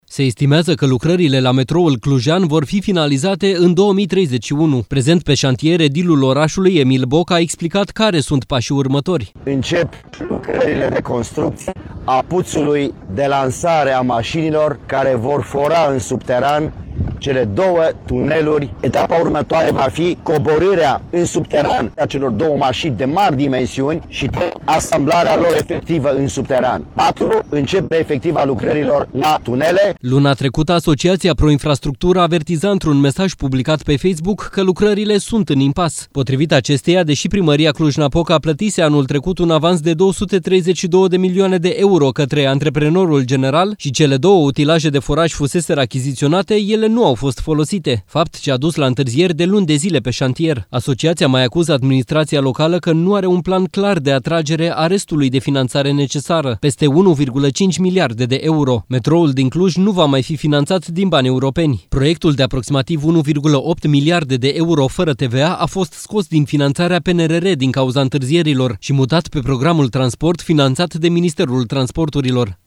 Emil Boc a prezentat pașii care trebuie urmați pentru construirea metroului
Prezent pe șantier, edilul orașului, Emil Boc, a explicat care sunt pașii următori: